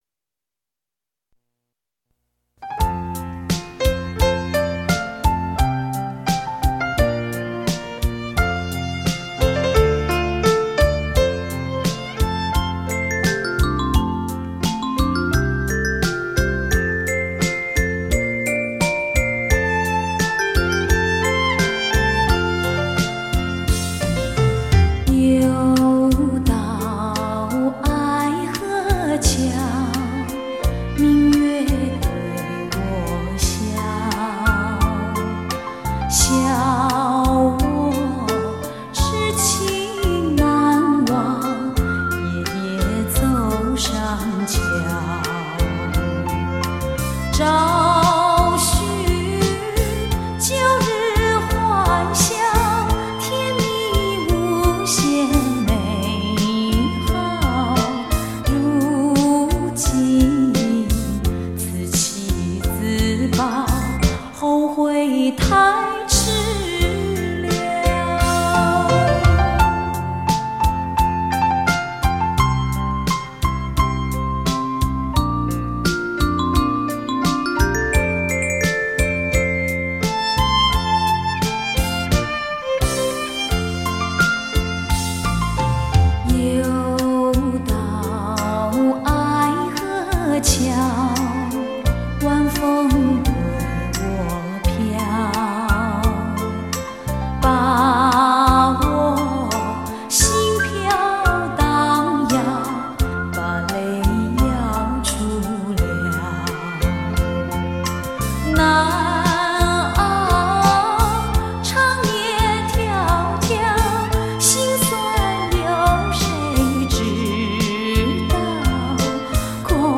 国语情歌